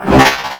general / combat / ENEMY / droid / att1.wav